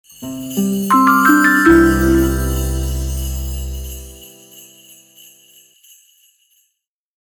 Virgule noël 3